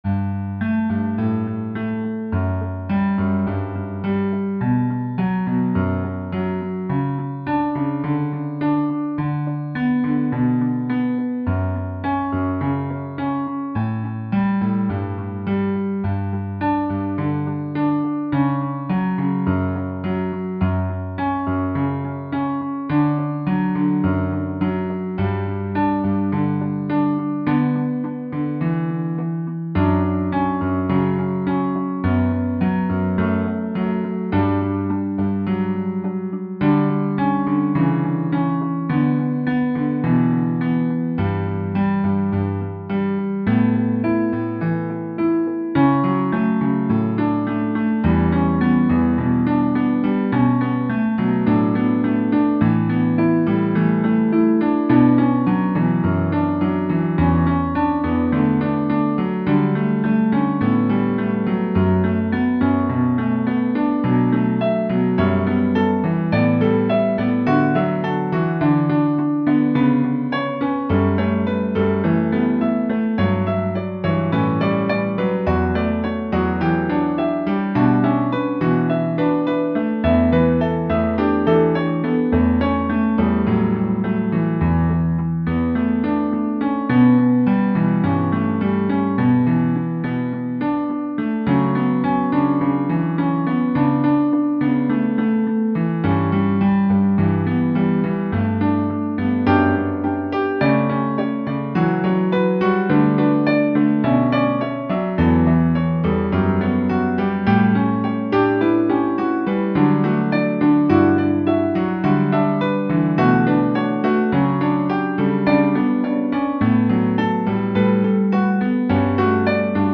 Hopalong ( 0.7 ) Mi (E) Penta 92